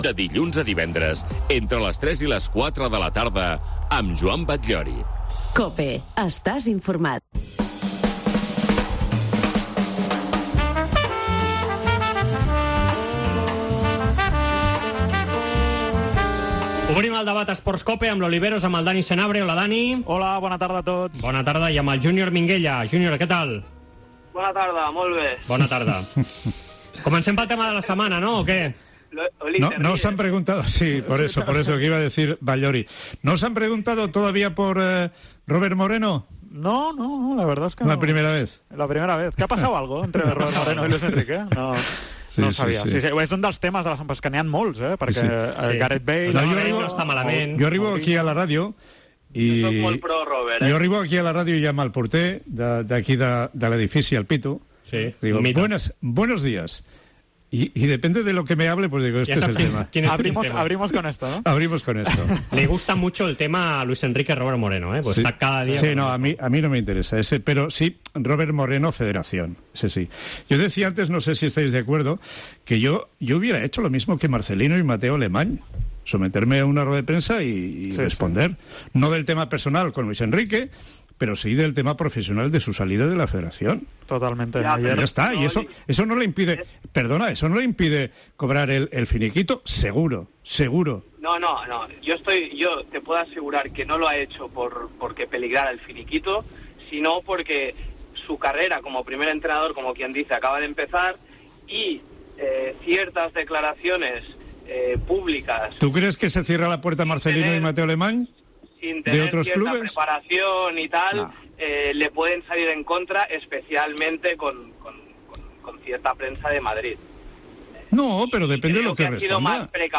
Aquí pots tornar a escoltar el debat del dijous